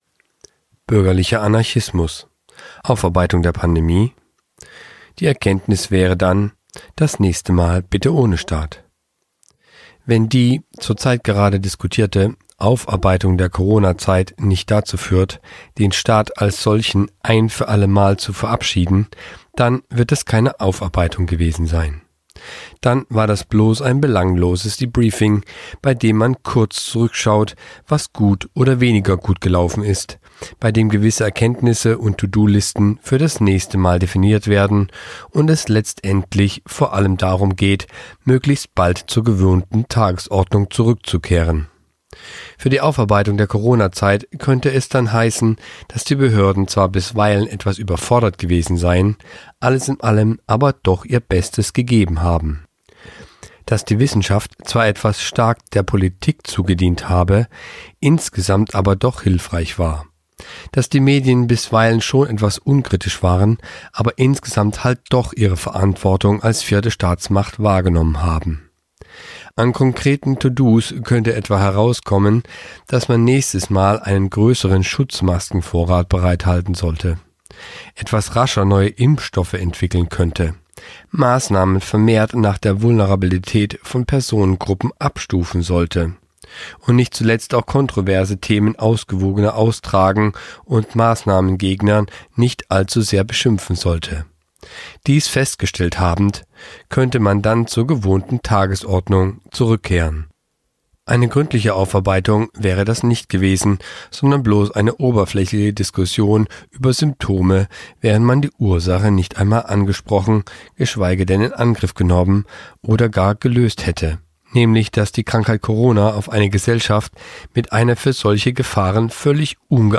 vertont